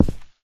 carpet_place.ogg